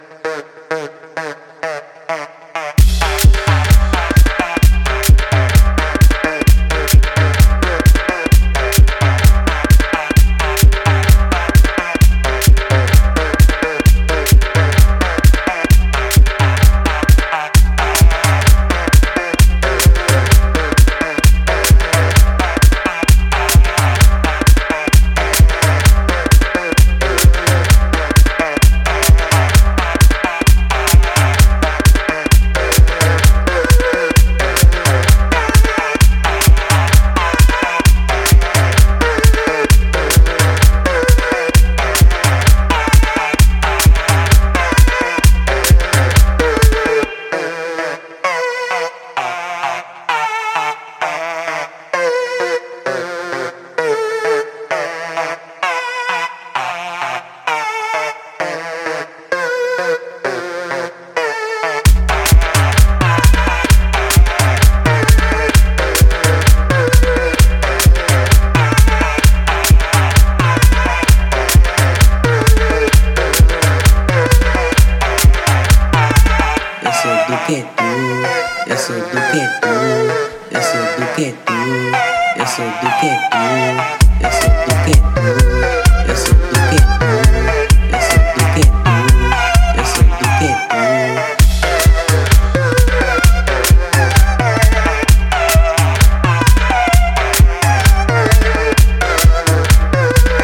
Mais club-ready do que o seu último disco
afro house